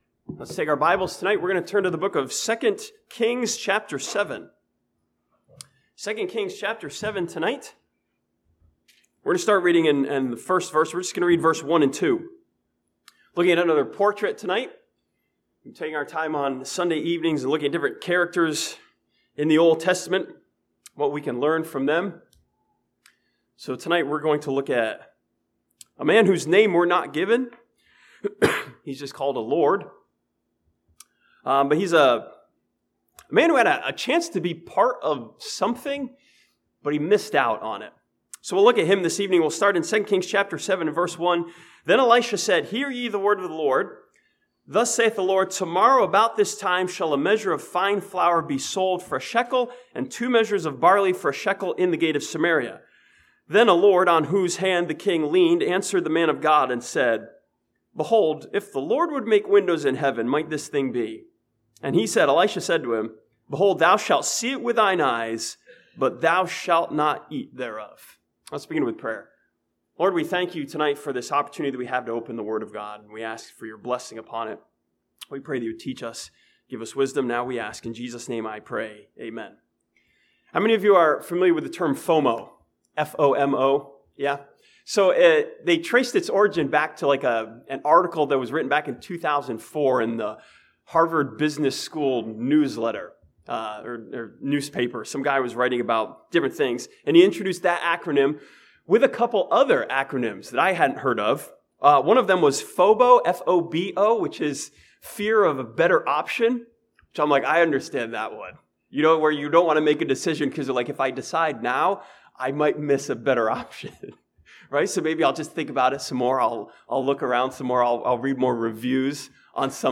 This sermon from 2 Kings chapter 7 studies one man whose choice to not believe the word of God makes him a portrait of missing out.